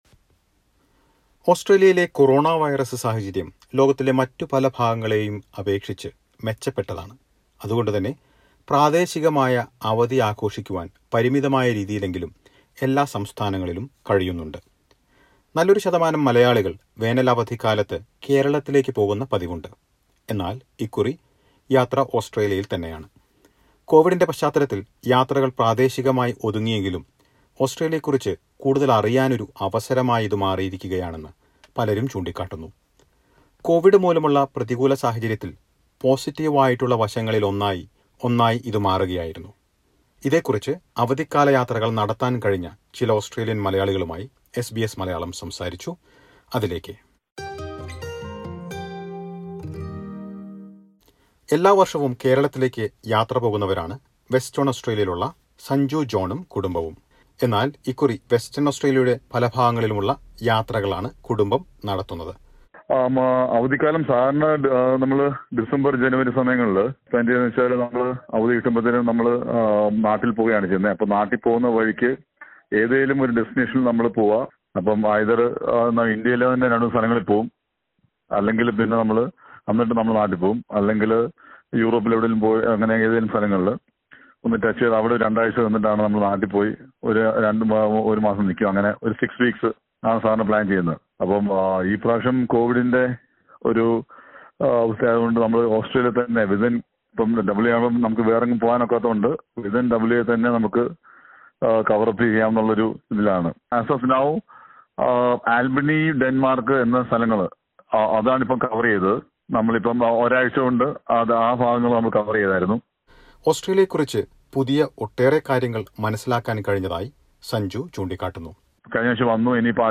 Holiday trips turned enitrely local this time around due to the coronavirus restrictions. But many take it as an opportunity to know Australia better. Listen to a report.